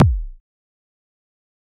EDM Kick 42.wav